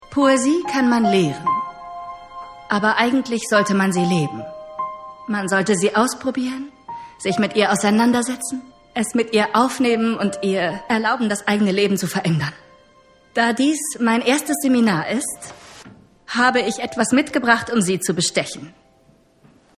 sehr variabel
Mittel minus (25-45)
Lip-Sync (Synchron)